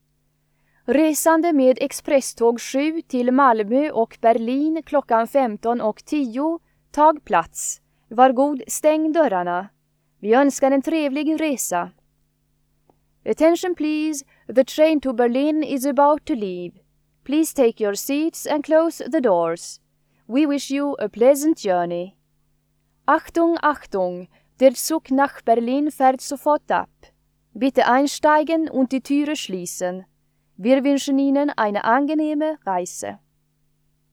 Högtalarutrop Stockholms central